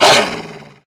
Divergent / mods / Soundscape Overhaul / gamedata / sounds / monsters / dog / attack_hit_0.ogg
attack_hit_0.ogg